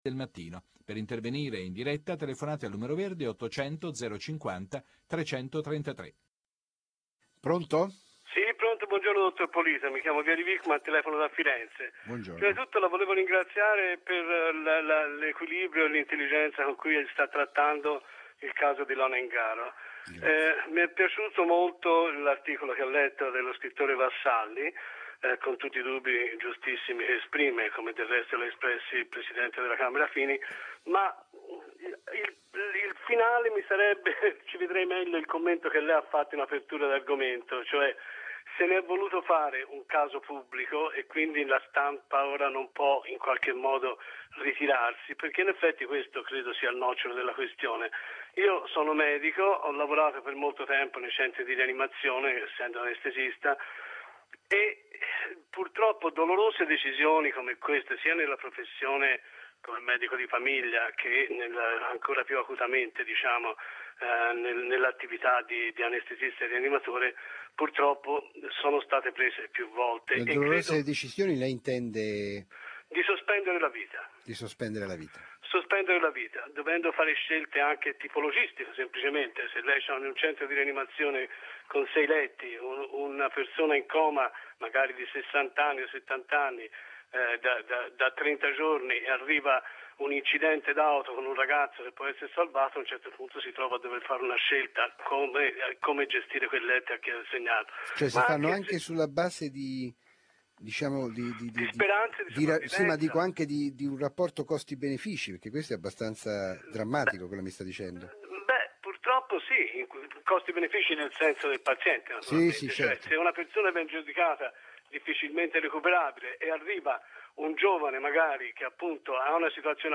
Consiglio l'ascolto dell' intervento di un medico anestesista alla trasmissione 'Primapagina' di RaiTre, condotta questa settimana da Antonio Polito.